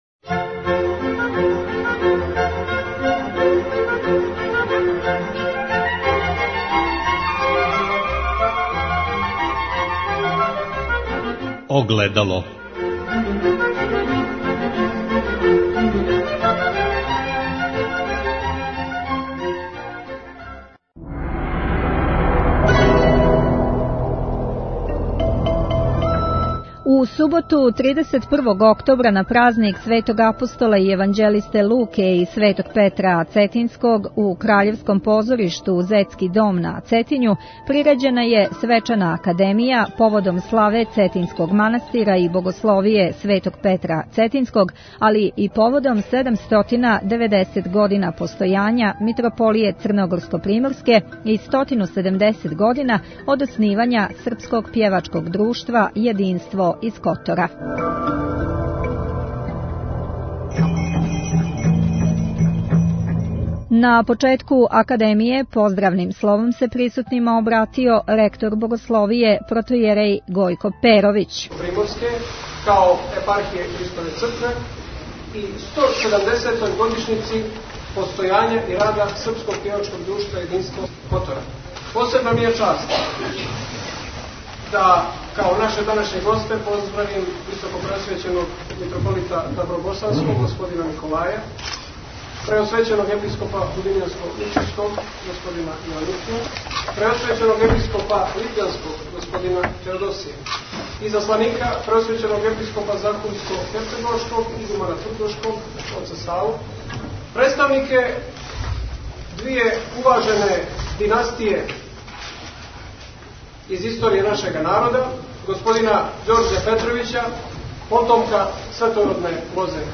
Запис са свечане академије која је поводом славе Цетињског манастира и Богословије Светог Петра Цетињског али и поводом 790 година постојања Митрополије Црногорско - приморске и 170 година од оснивања Српског пјевачког друштва "Јединство" из Котора, одржана у Краљевском позоришту "Зетски дом" на Цетињу на Лучиндан 31. октобра ове године. Празничну бесједу одржао је Његово Преосвештенство Епископ Диоклијски Игуман манастира Острог Г. Јован (Пурић).